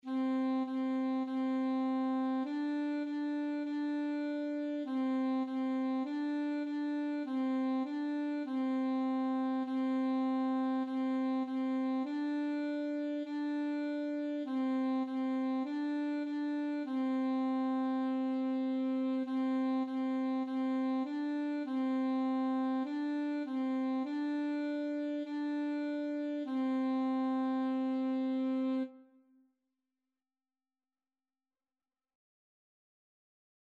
4/4 (View more 4/4 Music)
Saxophone  (View more Beginners Saxophone Music)
Classical (View more Classical Saxophone Music)